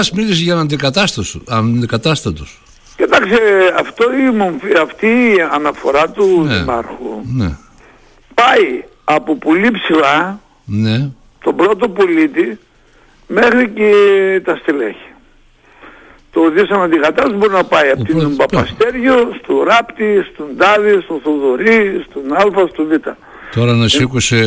στο ράδιο Ζυγός και στην εκπομπή «Χαμηλές Πτήσεις»